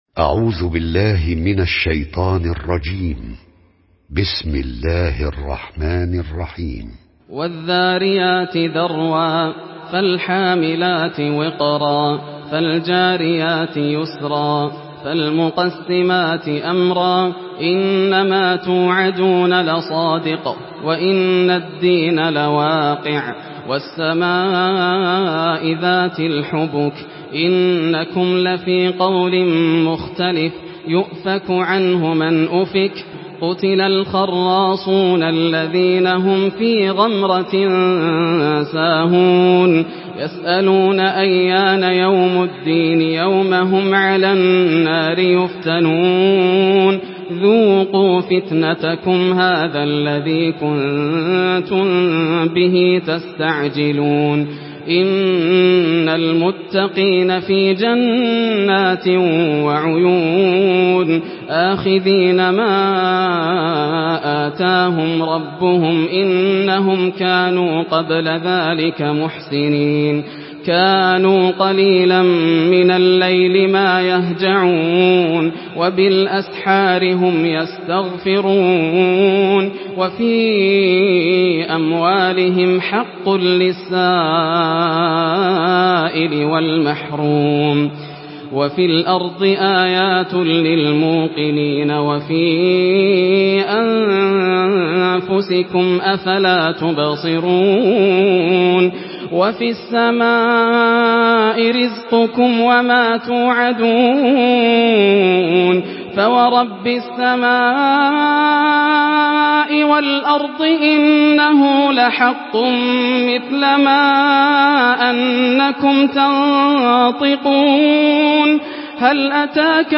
Surah Zariyat MP3 in the Voice of Yasser Al Dosari in Hafs Narration
Surah Zariyat MP3 by Yasser Al Dosari in Hafs An Asim narration.
Murattal